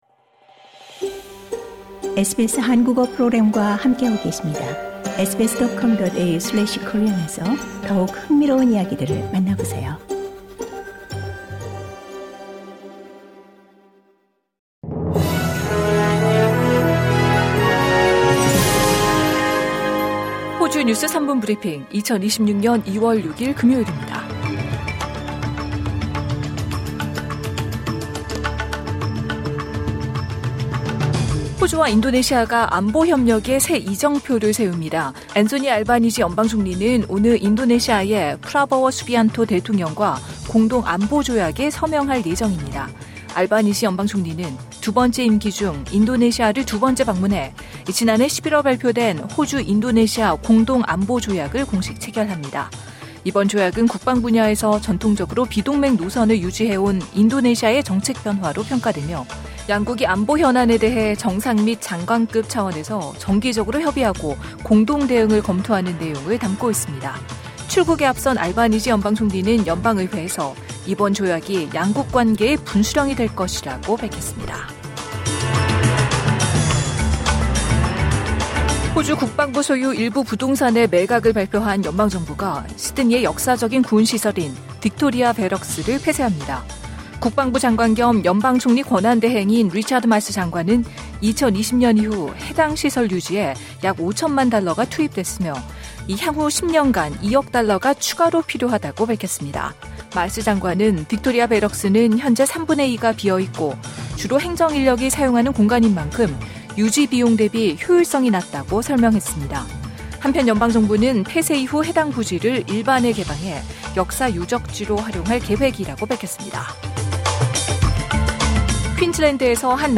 호주 뉴스 3분 브리핑: 2026년 2월 6일 금요일